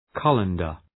{‘kʌləndər}